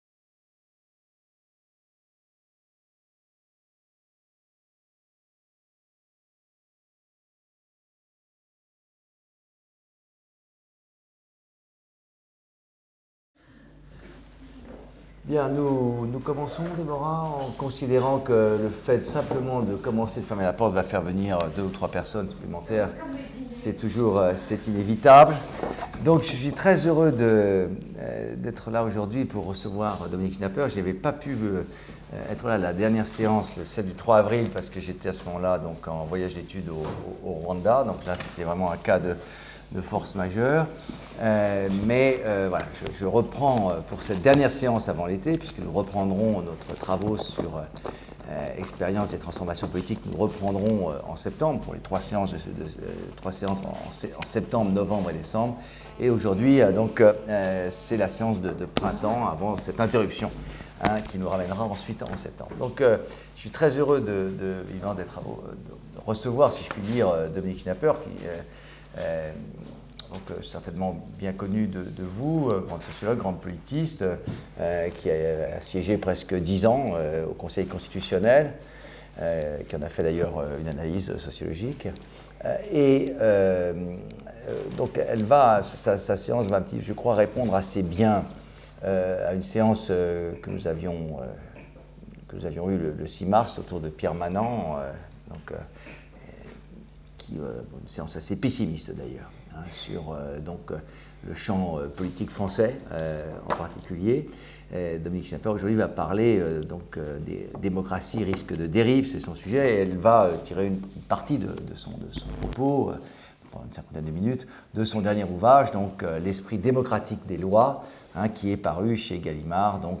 Séminaire dispensé par Dominique Schnapper Organisé en collaboration avec le labex Tepsis et le concours de l'Institut CDC pour la Recherche